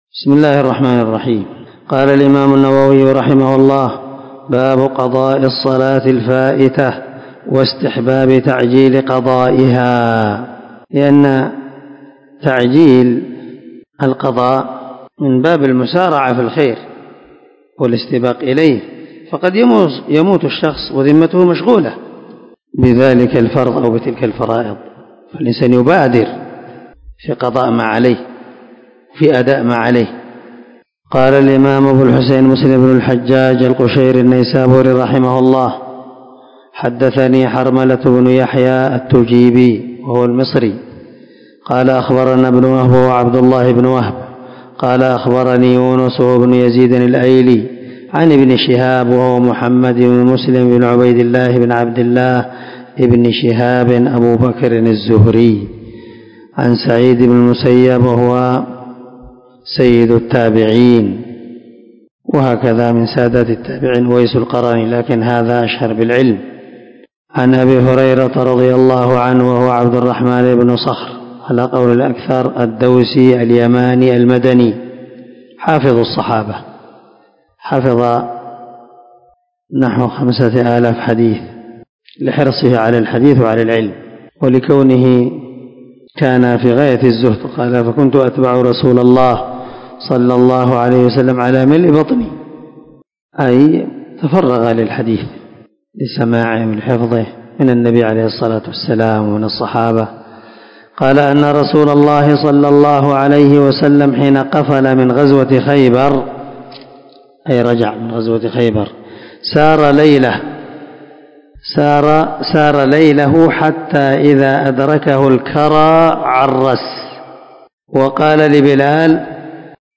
430الدرس 102 من شرح كتاب المساجد ومواضع الصلاة حديث رقم ( 680 ) من صحيح مسلم